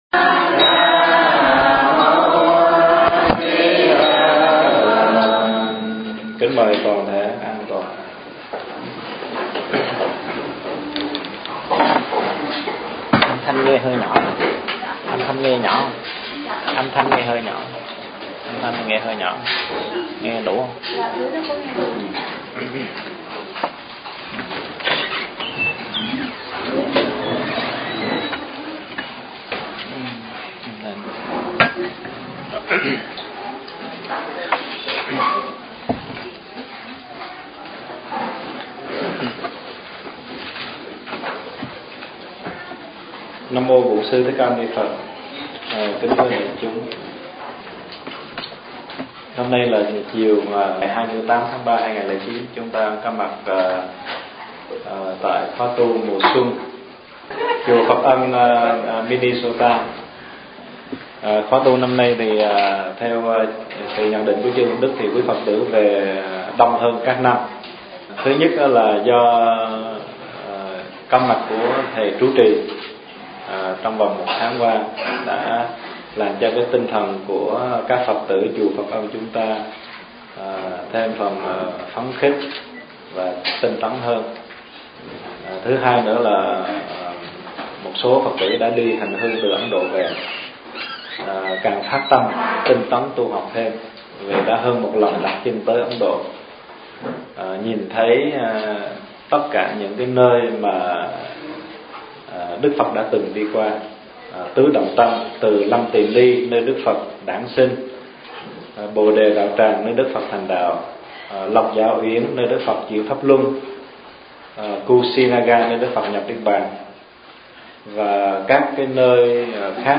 Thiền Sư Cảnh Sầm - Pháp Thoại (Audio) - Trang Nhà Quảng Đức